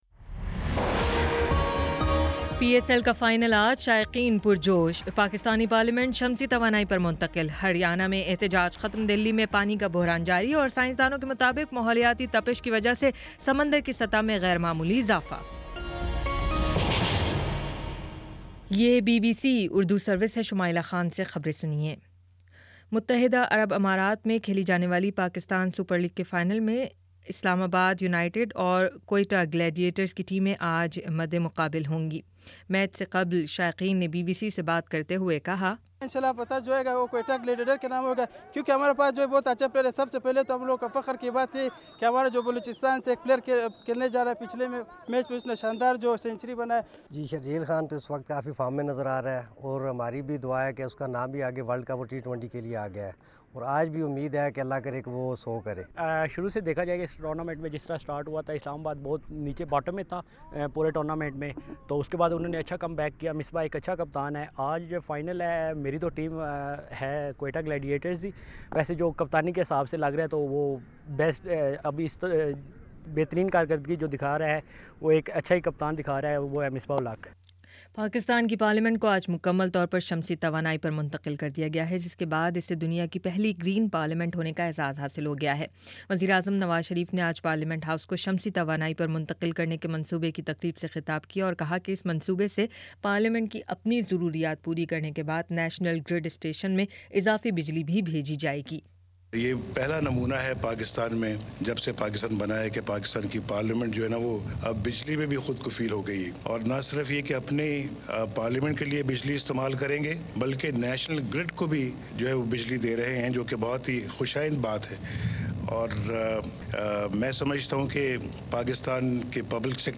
فروری 23 : شام چھ بجے کا نیوز بُلیٹن